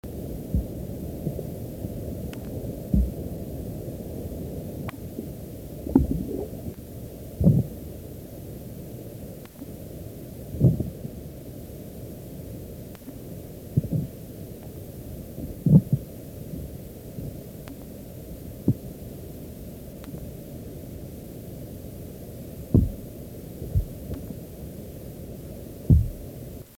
Sounds Made by Albula vulpes
Type of sound produced thumps, booms
Sound production organ teeth & swimbladder
Sound mechanism vibration, stridulation
Behavioural context under duress